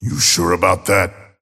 Abrams voice line - You sure about that?